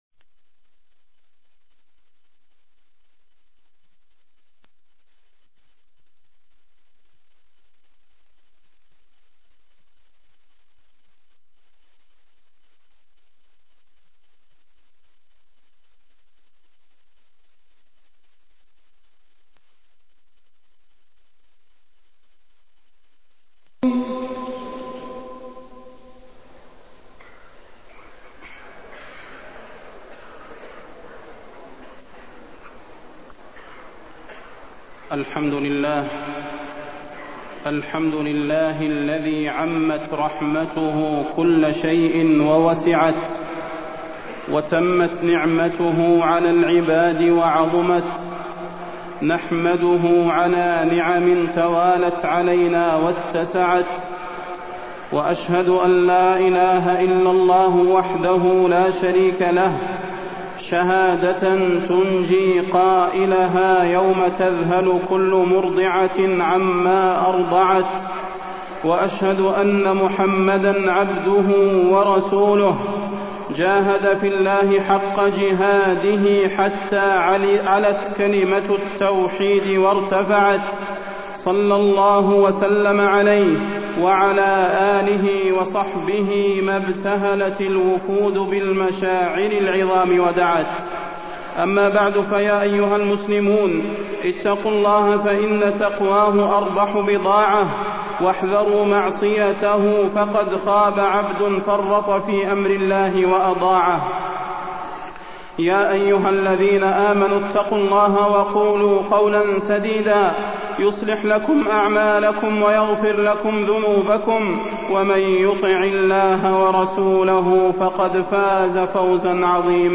تاريخ النشر ١٥ ذو الحجة ١٤٢٤ هـ المكان: المسجد النبوي الشيخ: فضيلة الشيخ د. صلاح بن محمد البدير فضيلة الشيخ د. صلاح بن محمد البدير توجيهات للحجاج